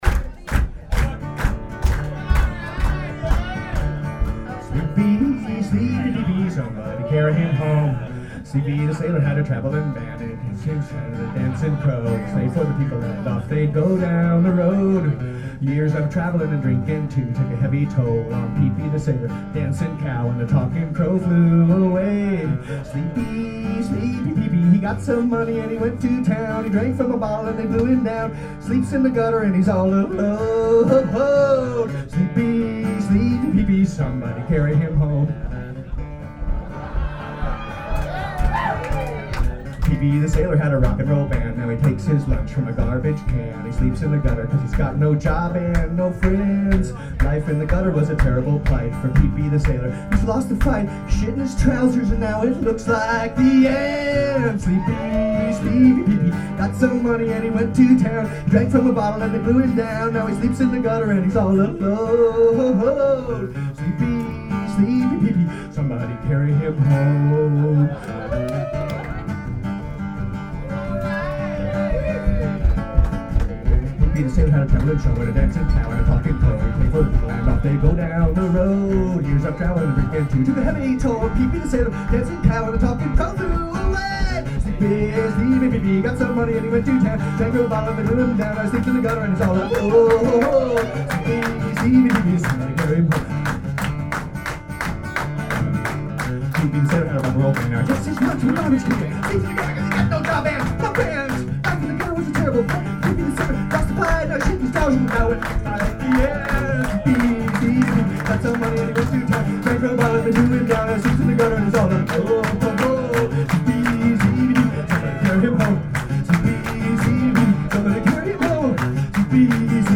Well, this show was a blast.